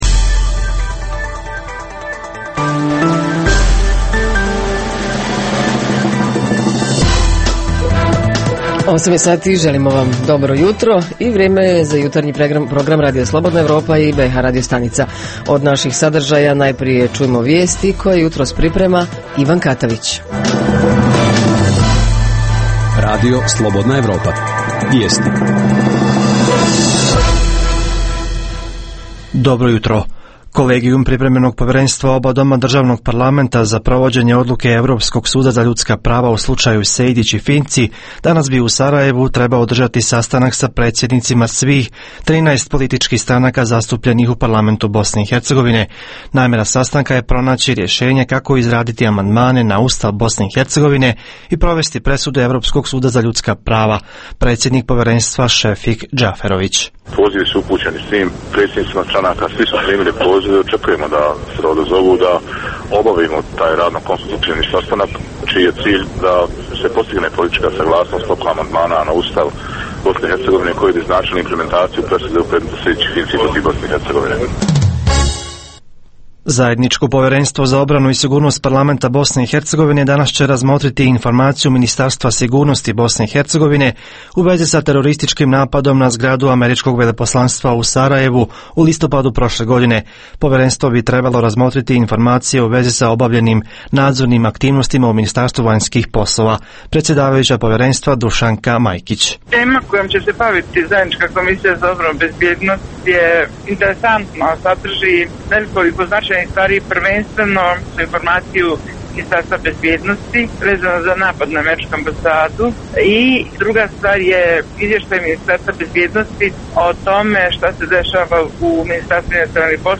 Tema jutra: Koliko se učenici educiraju po pitanju seksualnog i reproduktivnog zdravlja kroz obrazovni sistem osnovnog i srednjeg obrazovanja, na koji način, kroz koji program, da li postoji inicijativa da se uvede kao obavezan predmet? Reporteri iz cijele BiH javljaju o najaktuelnijim događajima u njihovim sredinama.
Redovni sadržaji jutarnjeg programa za BiH su i vijesti i muzika.